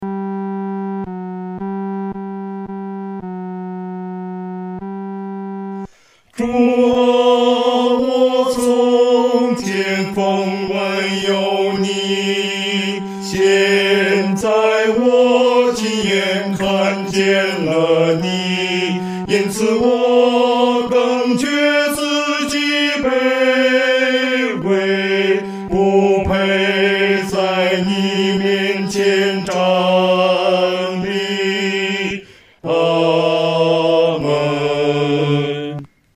男高
指挥在带领诗班时，表情和速度应采用温柔而缓慢地。